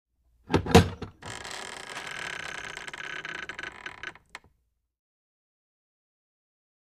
Creak, Door
Heavy Metal Latch Open And Door Creaks